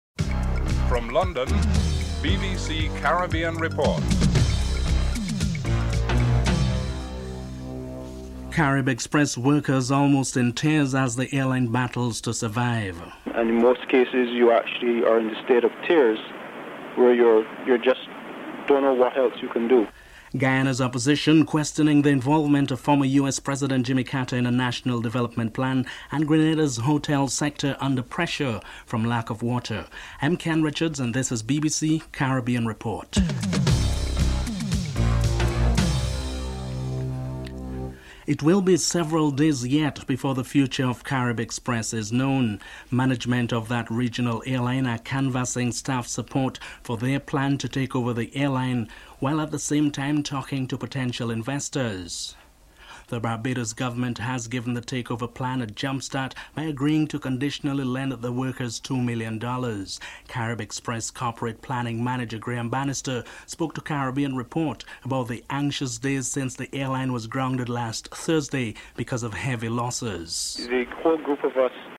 1. Headlines (00:00-00:37)
Minister Seymour Mullings is interviewed (07:23-10:05)